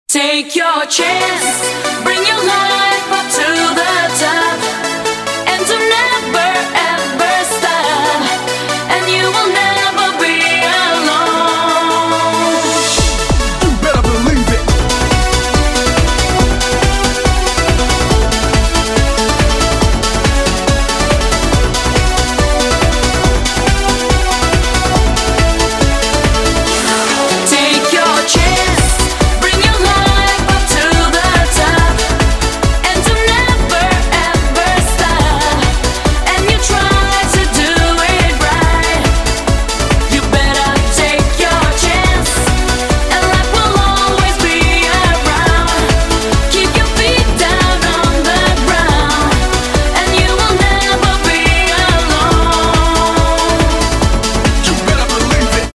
• Качество: 320, Stereo
громкие
женский вокал
dance
Eurodance
techno
ретро
90е